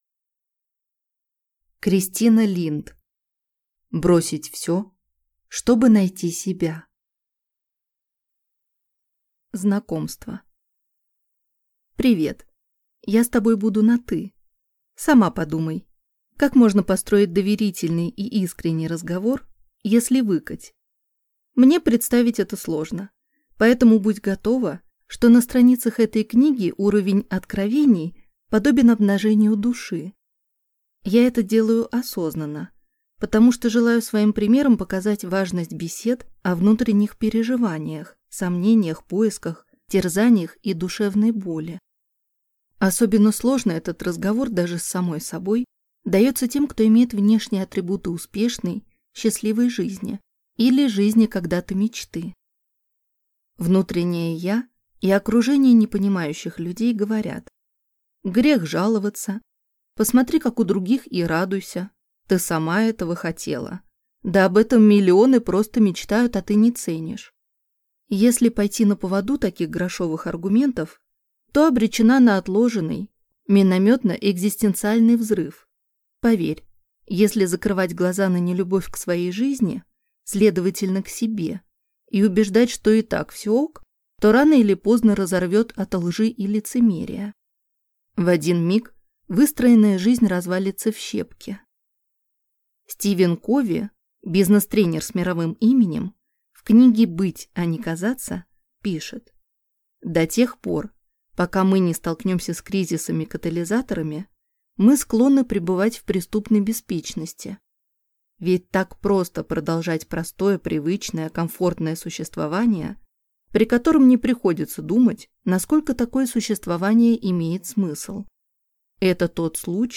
Аудиокнига Бросить всё, чтобы найти себя | Библиотека аудиокниг